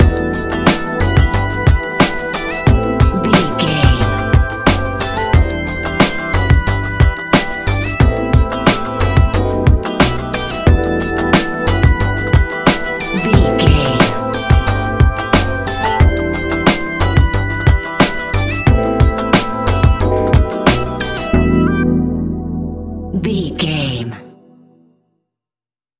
Ionian/Major
E♭
laid back
Lounge
sparse
new age
chilled electronica
ambient
atmospheric
instrumentals